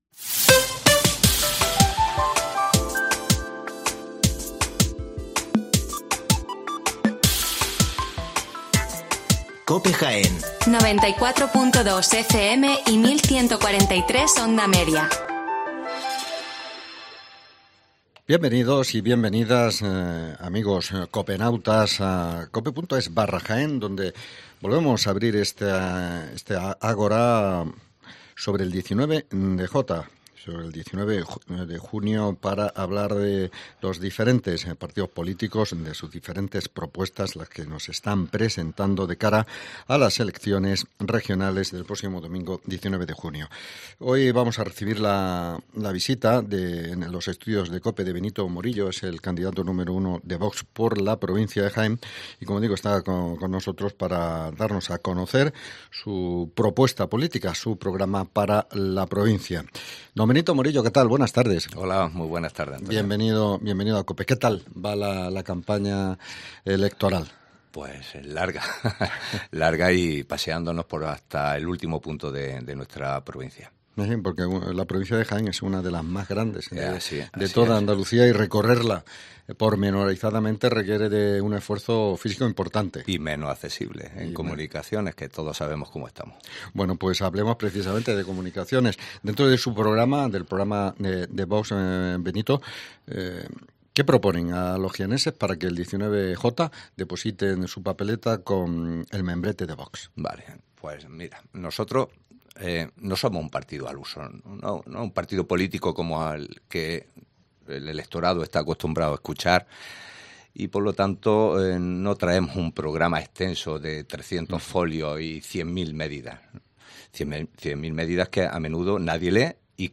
Hoy charlamos con el cabeza de lista de VOX para la provincia de Jaén, Benito Morillo.
Benito Morillo repite como cabeza de lista de Vox a las elecciones andaluzas por la provincia de Jaén y hoy ha estado en los estudios de COPE para exponer las líneas fundamentales de su oferta programática para el 19 de Junio. Morillo ha hablado del "eterno" olvido que sufre la provincia de Jaén, de la necesidad de construir las infraestructuras necesarias que mejoren su comunicación y de la atención, específica, que se merece el sector primario.